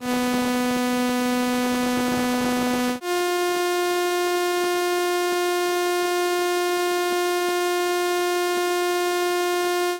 Stimmtöne Arkadische Botschaften I mp3
micro_guitar2VI.mp3